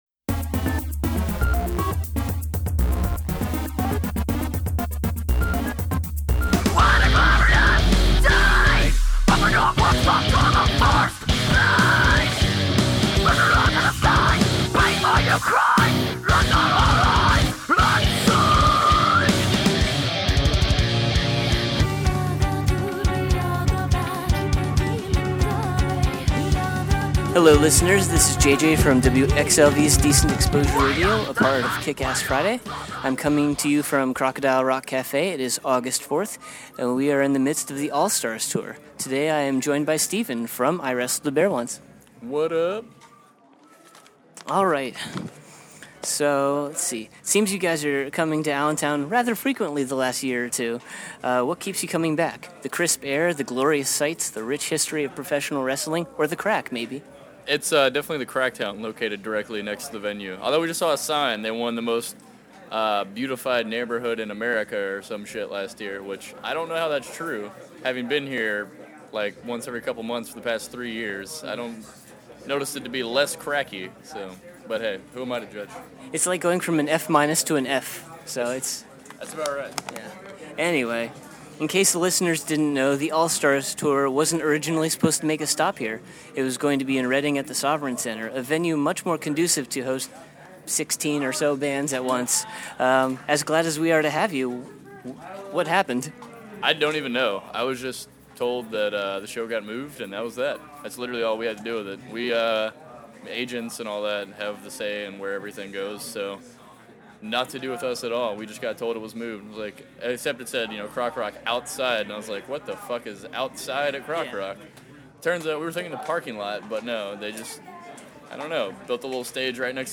Exclusive: iwrestledabearonce Interview
09-interview-iwrestledabearonce.mp3